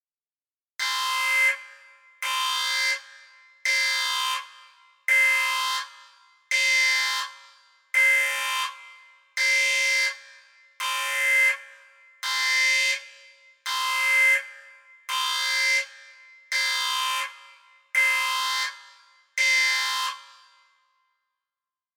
Sound Effect
Spaceship Alarm
Spaceship_Alarm.mp3